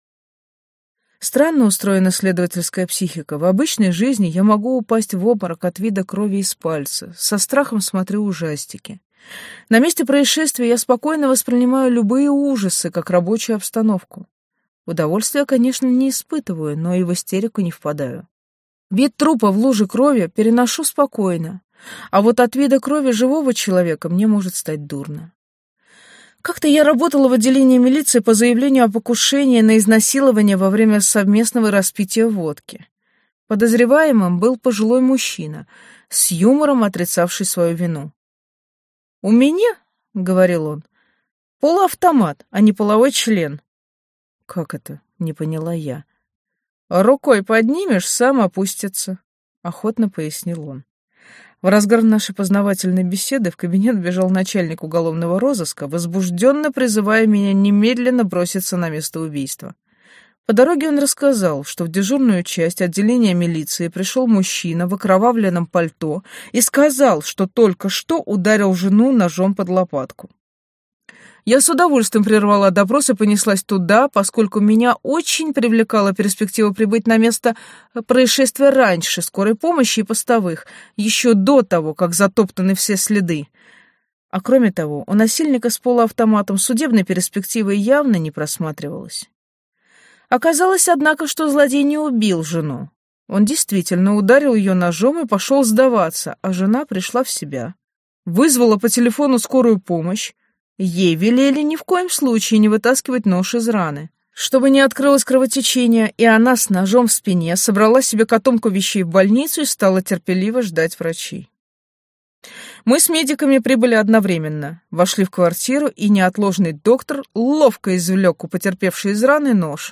Аудиокнига Записки сумасшедшего следователя | Библиотека аудиокниг